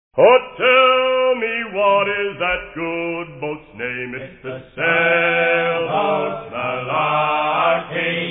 in front of a select audience